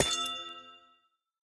Minecraft Version Minecraft Version latest Latest Release | Latest Snapshot latest / assets / minecraft / sounds / block / amethyst_cluster / break2.ogg Compare With Compare With Latest Release | Latest Snapshot
break2.ogg